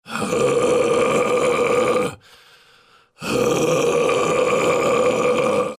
Verzerrter Gesang im Metal, Soundbeispiel mit Frage zum wie macht man dat genau?
Ich habe mir mal von YT einen Growl aufgenommen und gezerrt.